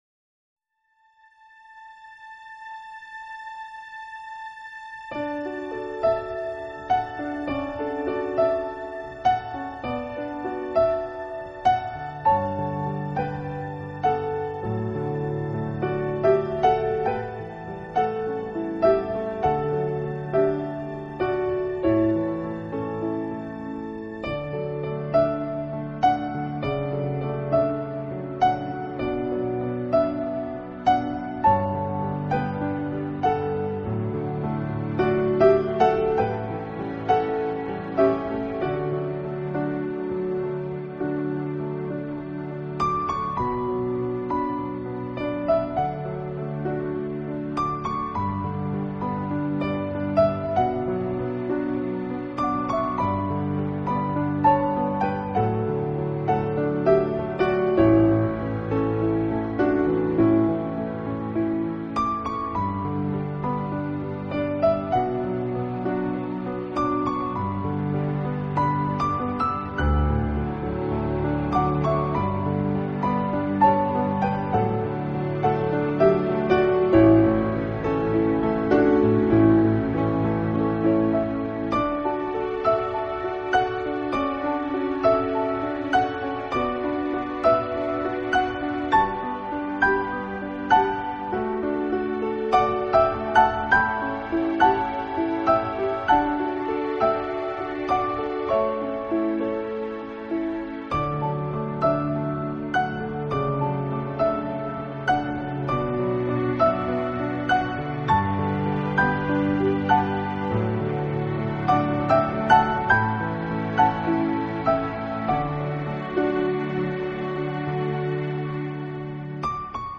音乐风格：New Age钢琴独奏